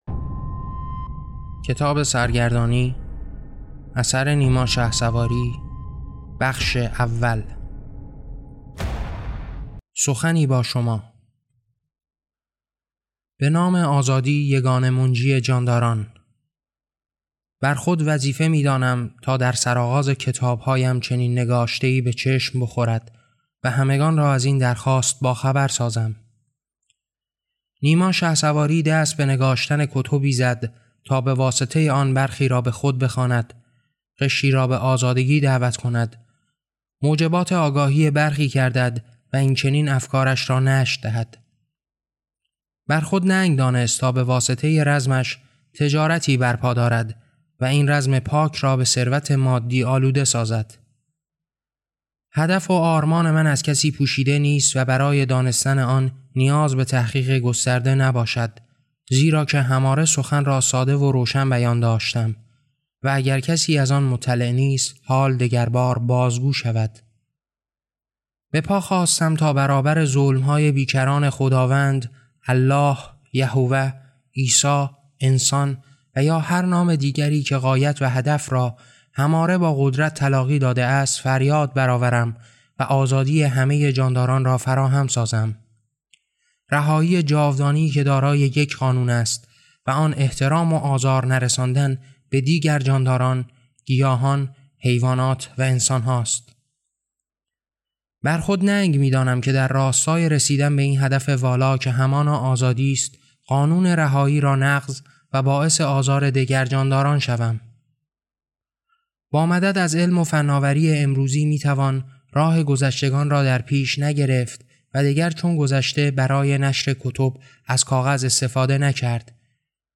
کتاب صوتی سرگردانی - بخش اول